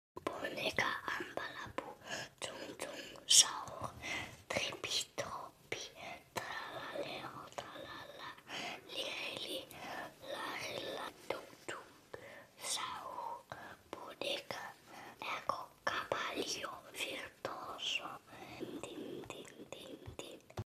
Mini ASMR Part 17.